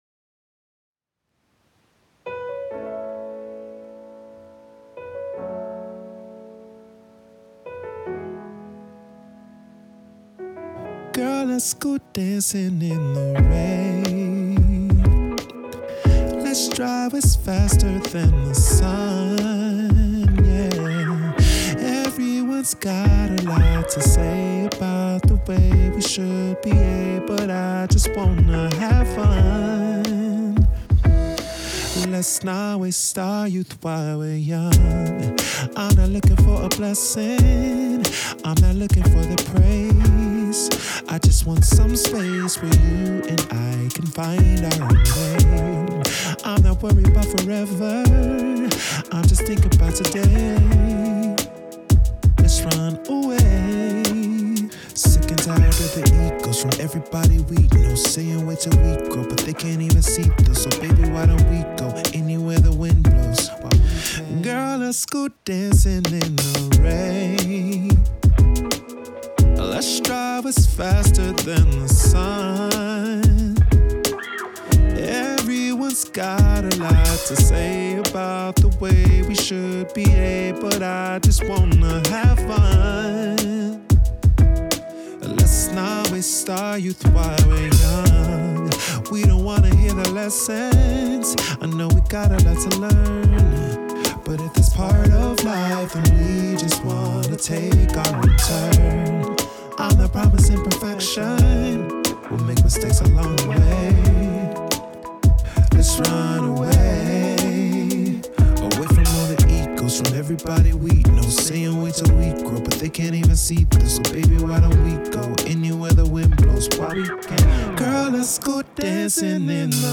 Piano, Guitar, Drum Programming